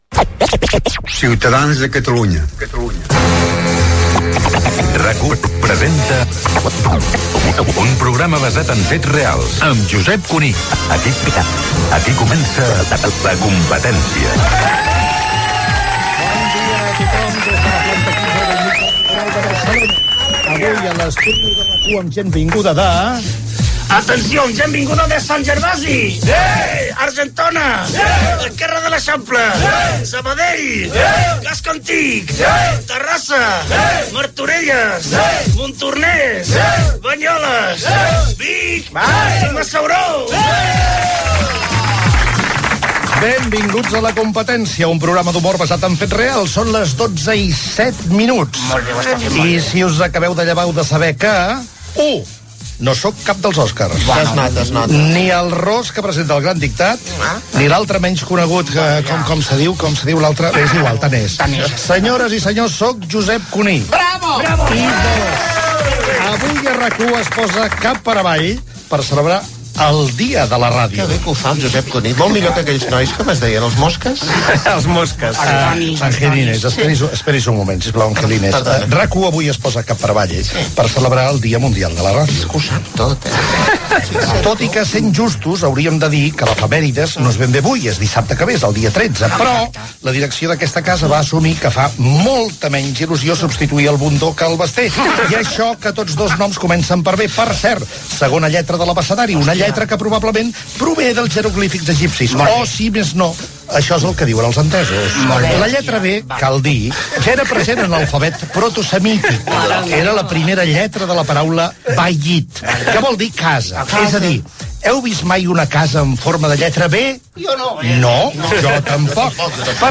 Careta del programa, localitats de l'audiència que està a l'estudi, Josep Cuní es presenta com el conductor del programa i parla de la lletra B, invitació a participar.
Gènere radiofònic Entreteniment